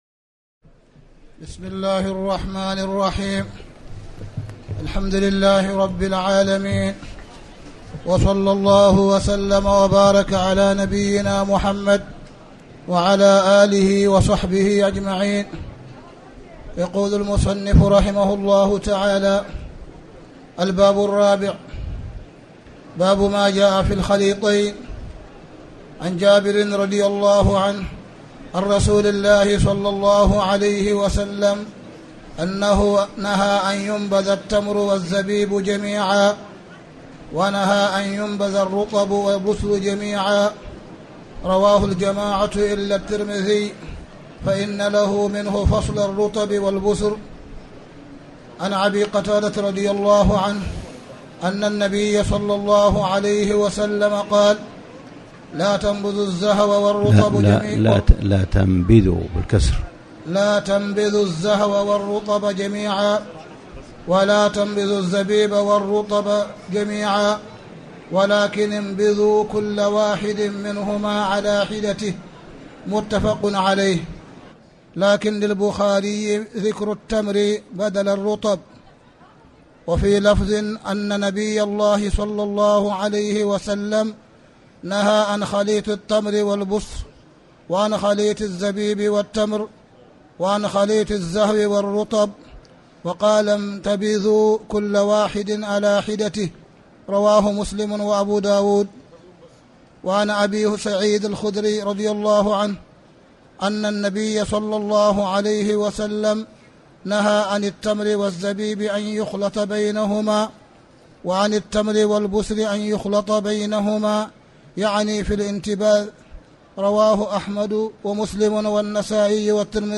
تاريخ النشر ٧ رمضان ١٤٣٩ هـ المكان: المسجد الحرام الشيخ: معالي الشيخ أ.د. صالح بن عبدالله بن حميد معالي الشيخ أ.د. صالح بن عبدالله بن حميد كتاب الأشربة